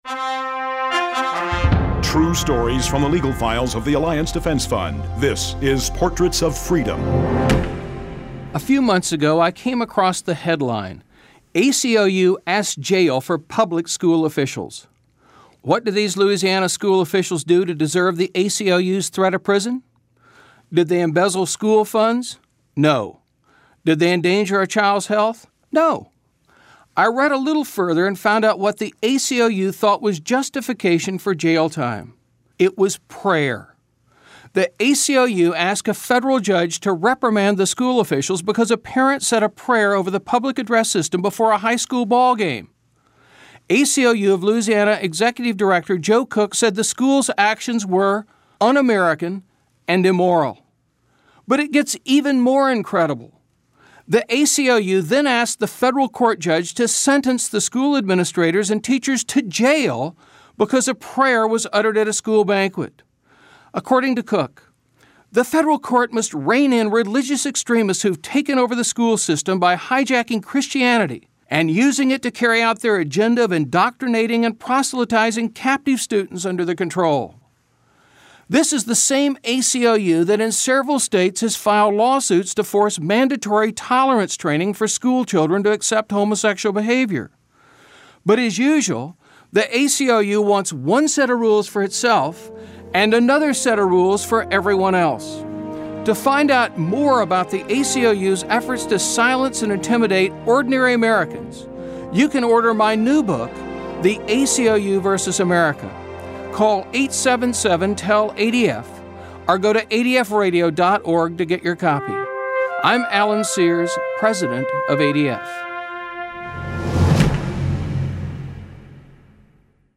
ADF Radio Clip: